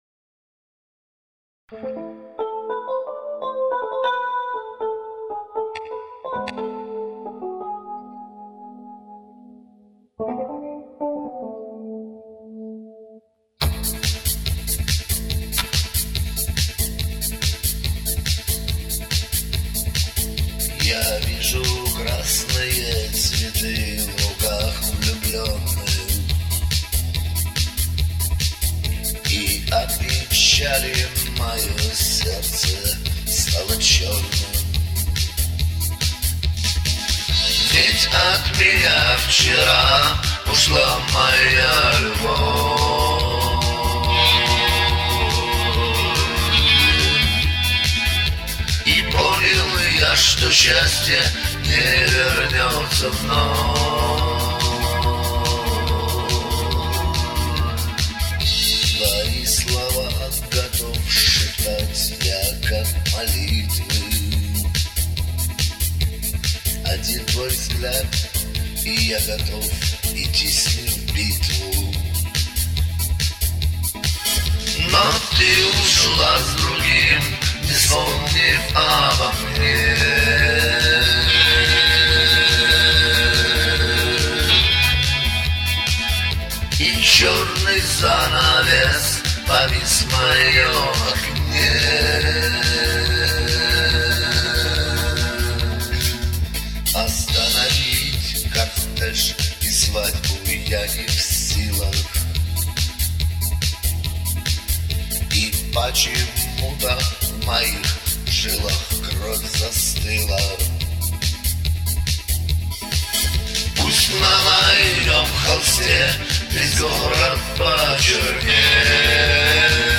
Но мне кажется слишком сильно подрезаны высокие?